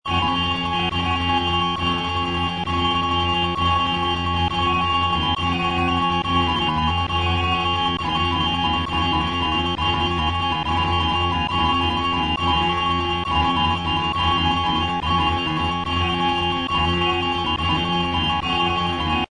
The sound samples used in this installation were created by forcing data flows through digital sound equipment wired into a feedback loop. While the resulting sounds appear to be repetitive, they contain random elements as well as periodic punctuations that produce subtle changes over time.
The method of creating this sound was developed in keeping with the principle of infonoise.
i_noise-sample7.mp3